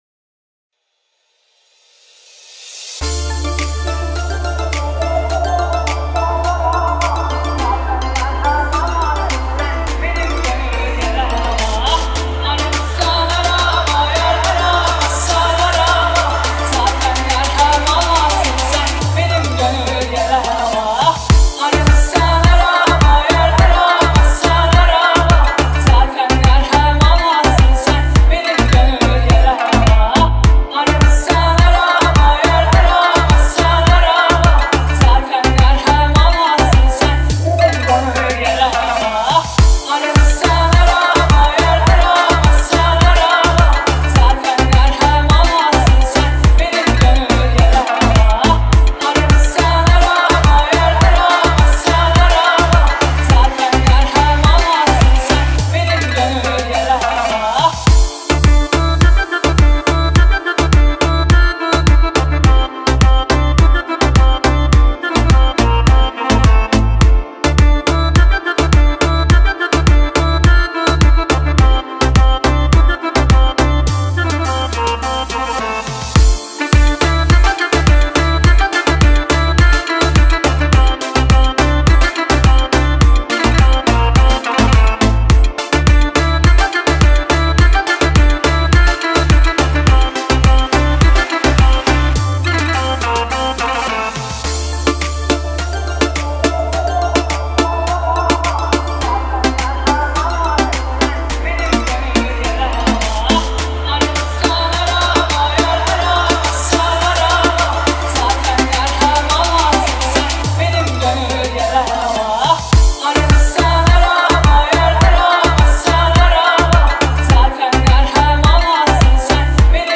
دانلود ریمیکس شاد ترکی